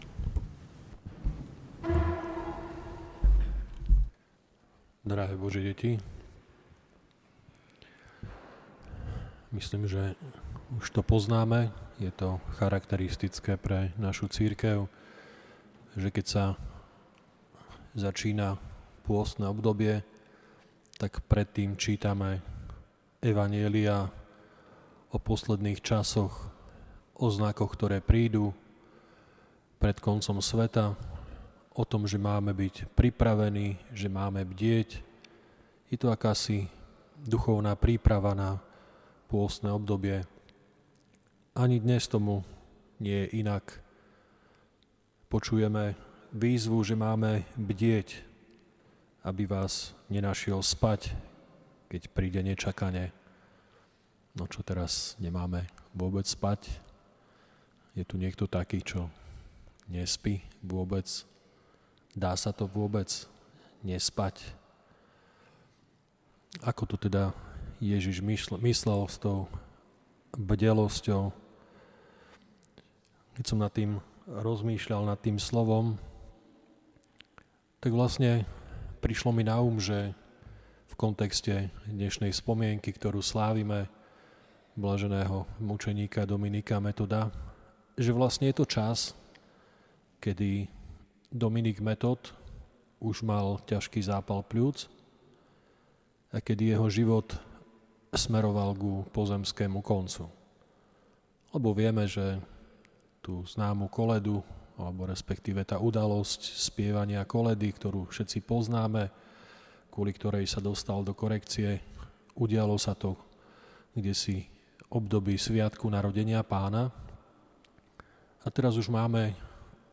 Po skončení svätej liturgie nasledovala modlitba o uzdravenie s pomazaním olejom.